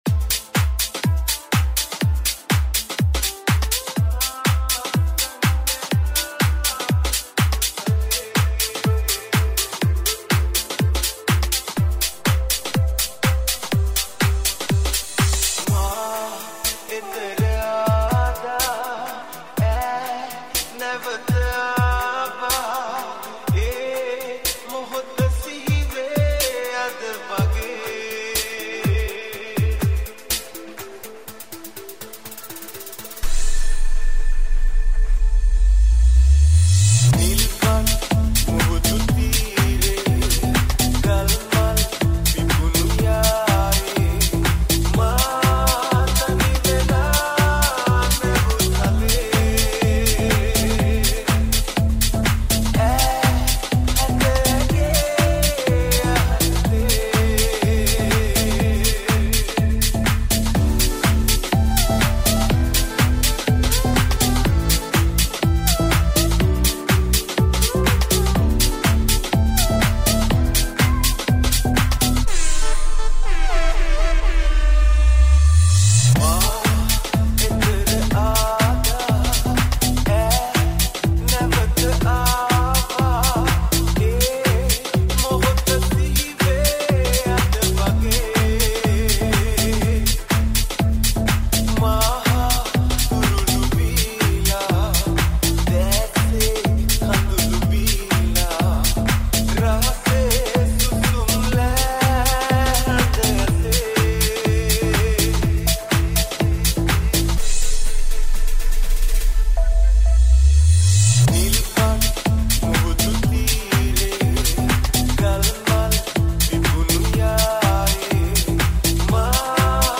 High quality Sri Lankan remix MP3 (3.9).